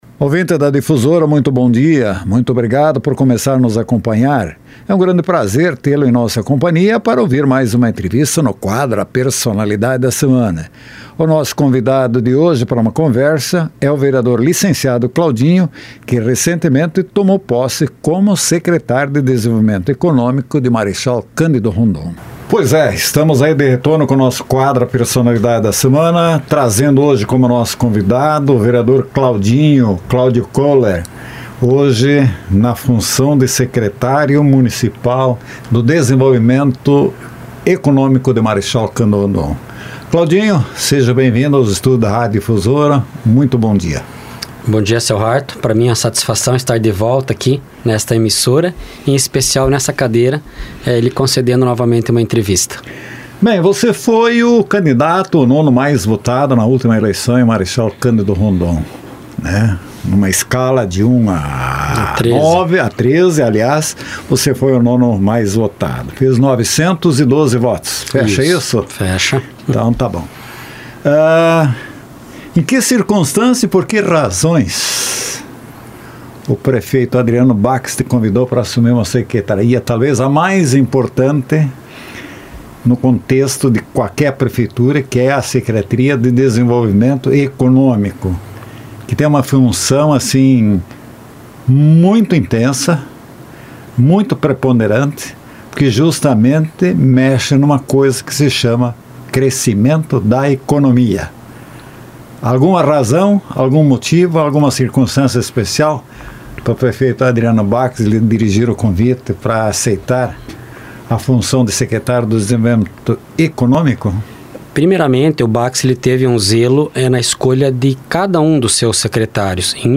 Claudio Koehler foi o nosso entrevistado em A Personalidade da Semana